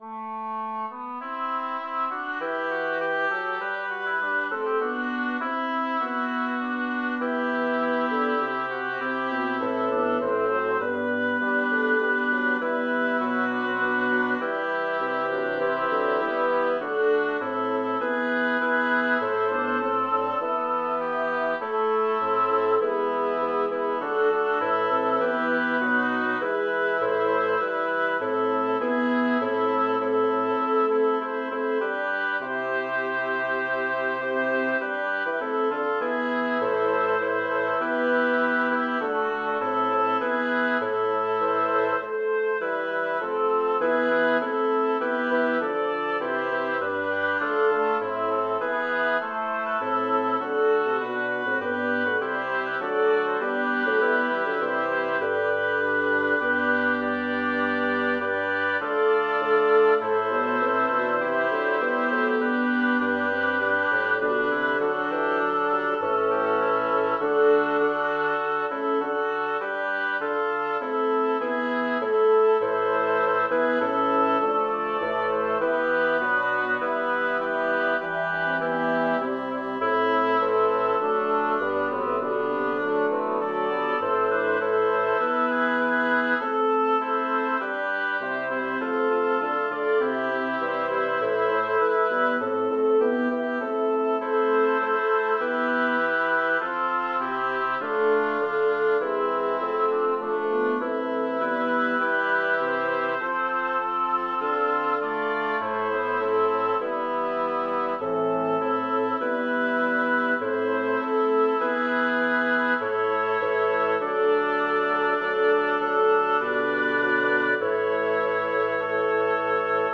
Number of voices: 6vv Voicing: SSATTB Genre: Sacred, Motet for Easter Vigil
Language: Latin Instruments: A cappella